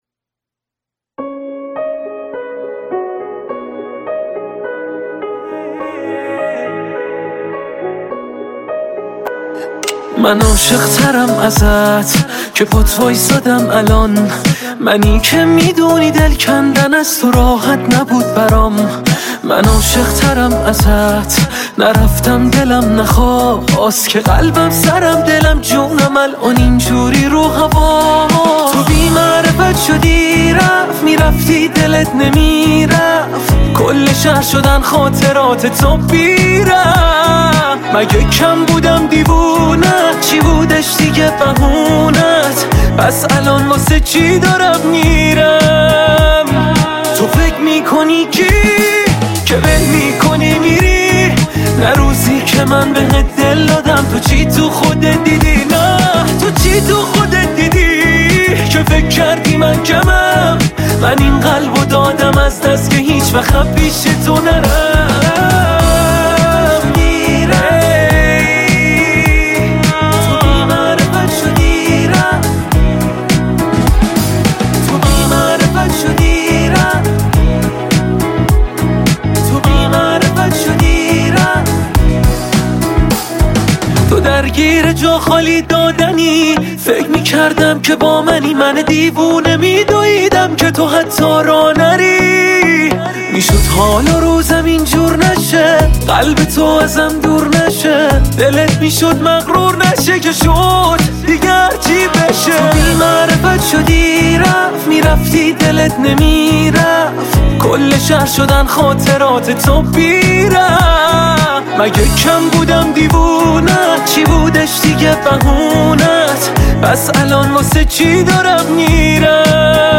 آهنگ شاد